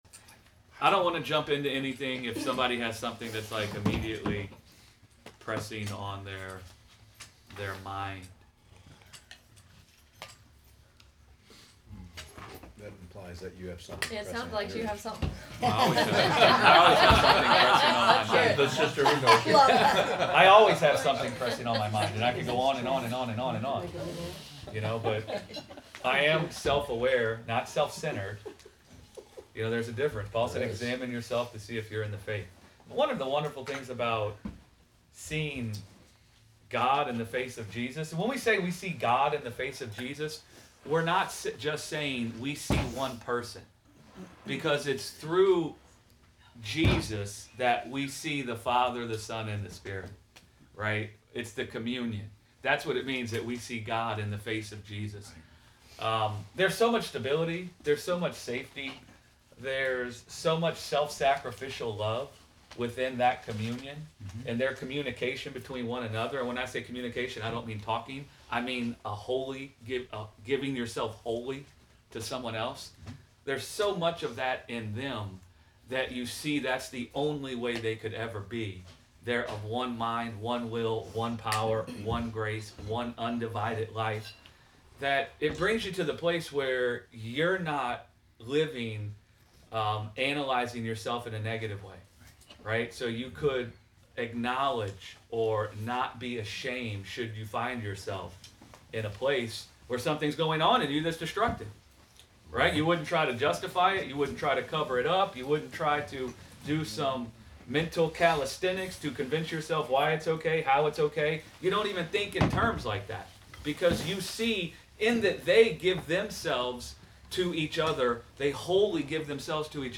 Sunday Bible Study: Family Logic - Gospel Revolution Church